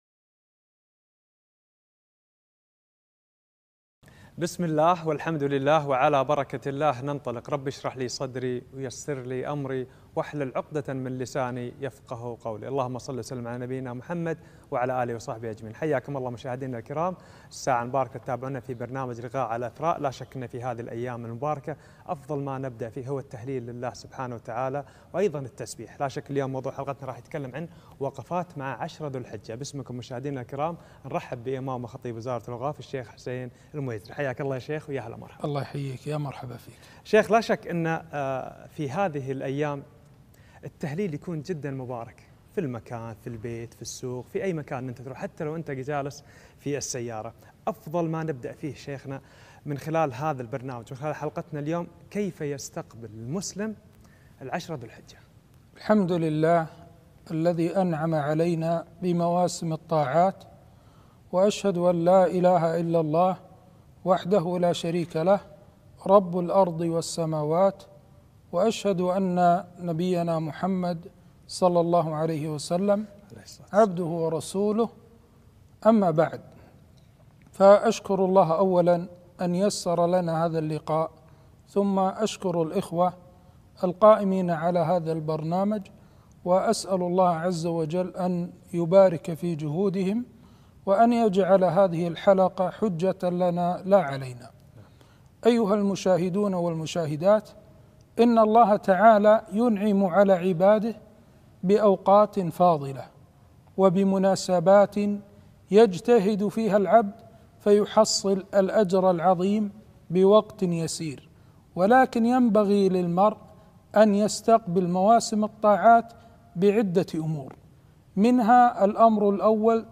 لقاء تلفزيوني - وقفات مع عشر ذي الحجة